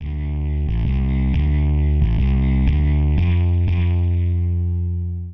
描述：缓慢扭曲的模糊低音在果味循环中制成
Tag: 90 bpm Rock Loops Bass Loops 918.89 KB wav Key : Unknown